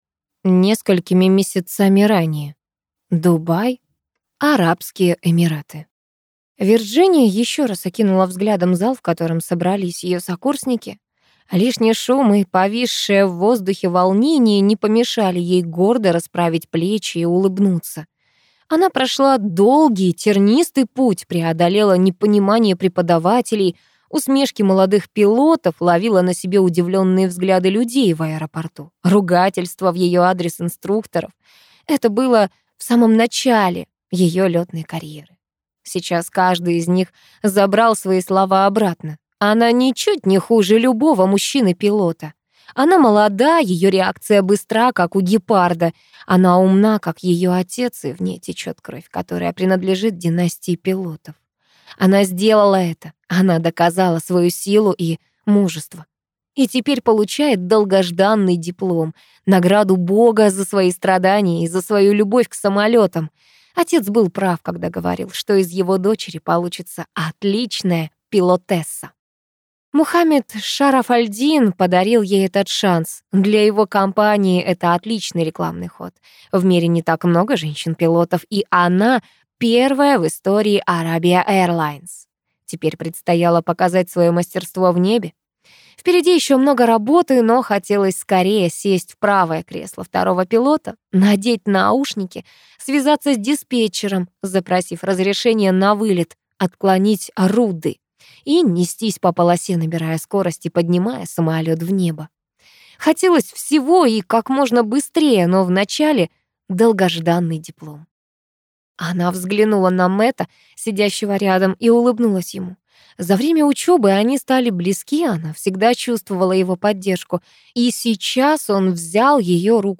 Аудиокнига Одно небо на двоих | Библиотека аудиокниг